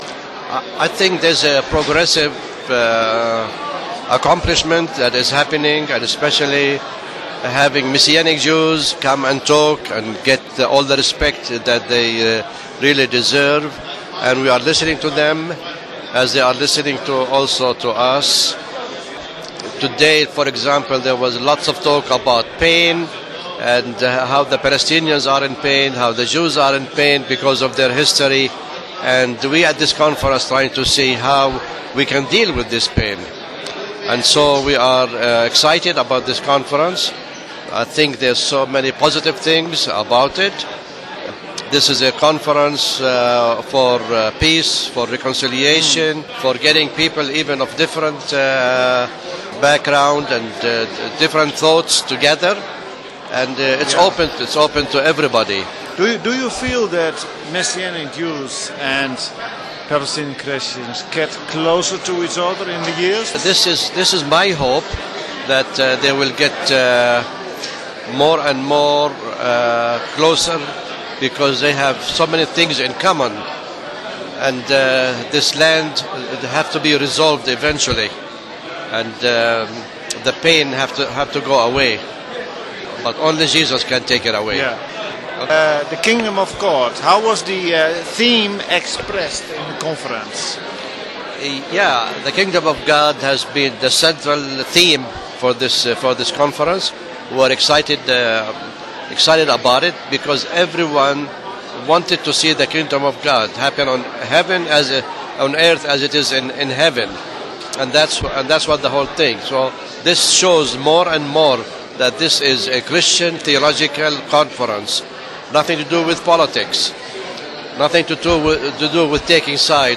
Het gesprek vond afgelopen woensdag plaats.